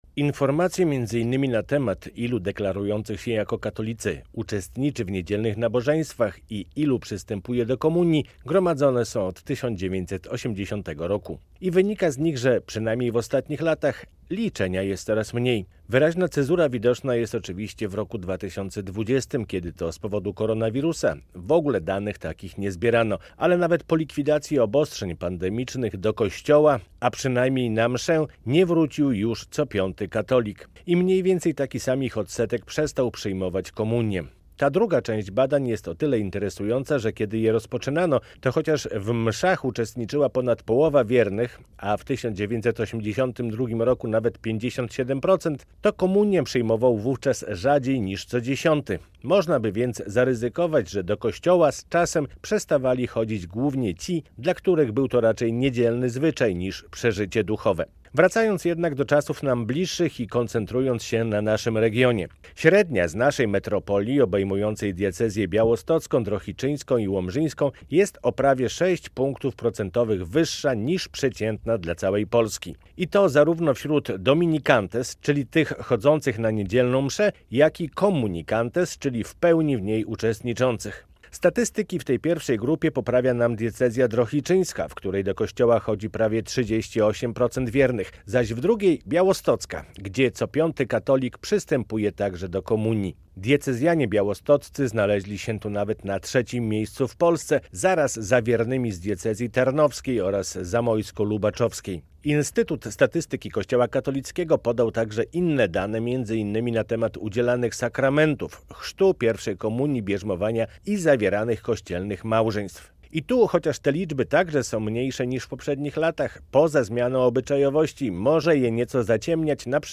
Felieton